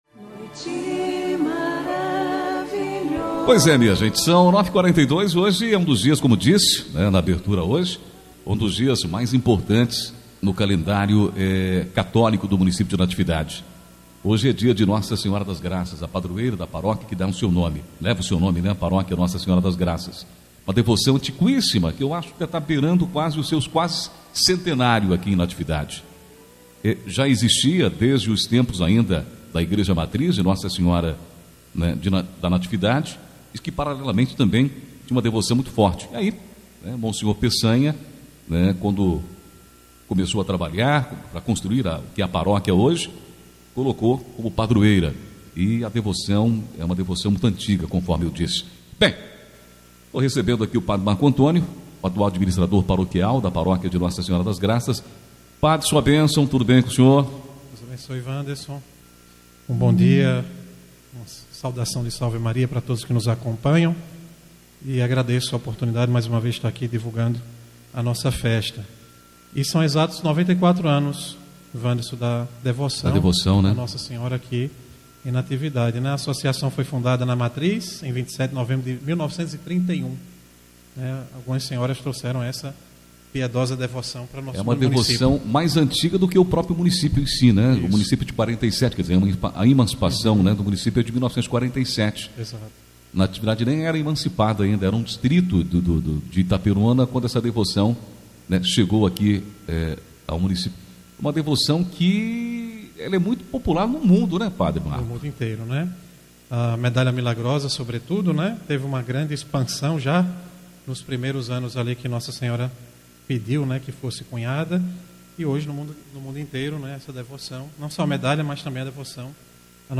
27 de novembro de 2025 DESTAQUE, ENTREVISTAS